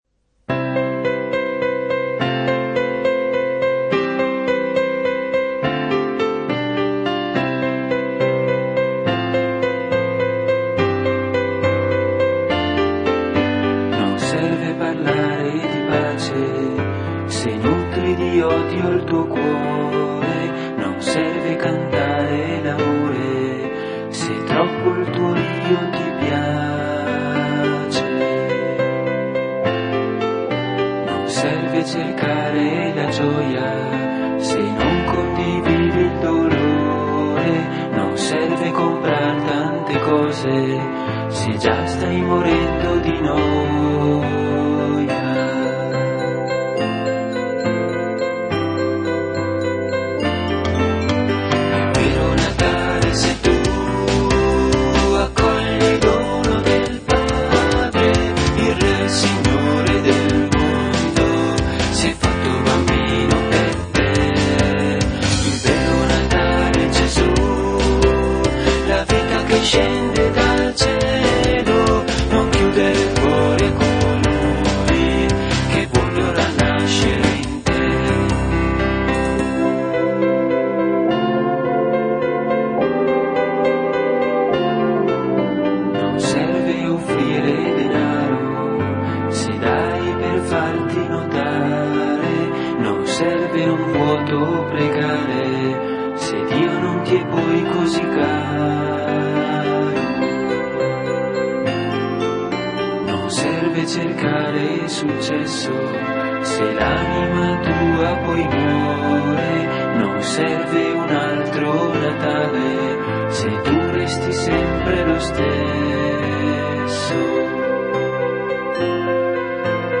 armonizzate, arrangiate ed eseguite in versione strumentale
al sintetizzatore